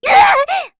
Rehartna-Vox_Damage_kr_06.wav